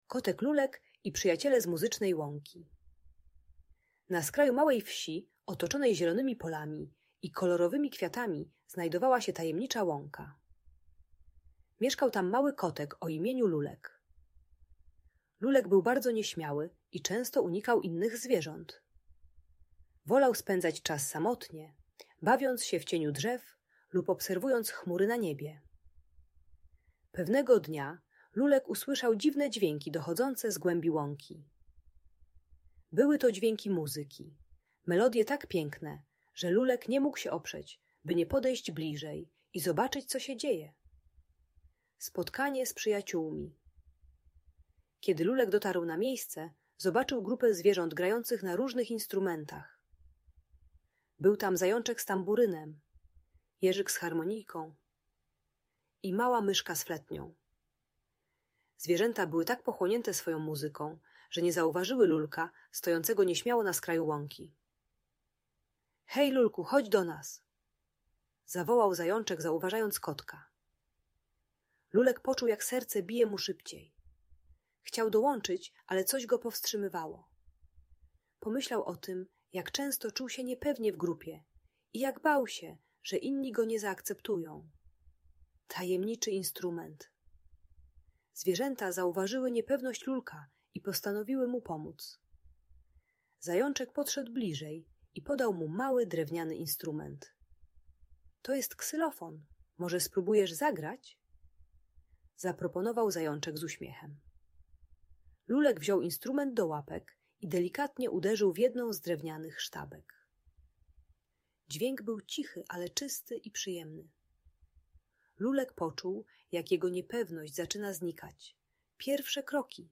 Historia o Kocie Lulku i Muzycznej Łące - Audiobajka